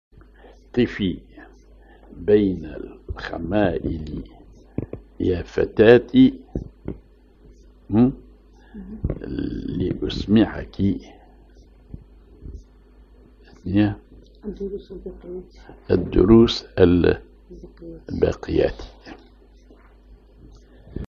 genre نشيد